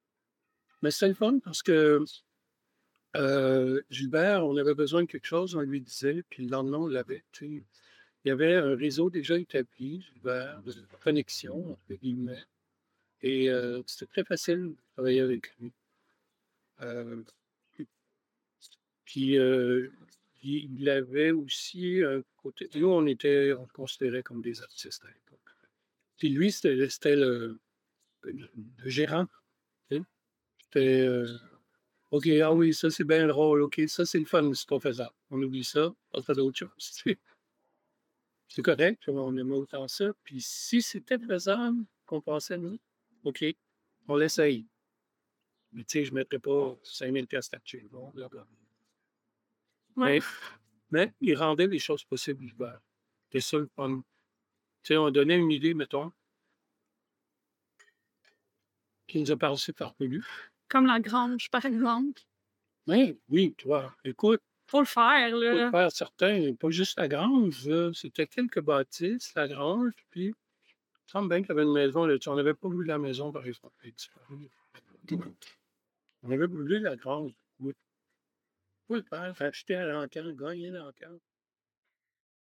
Audio excerpt: Interview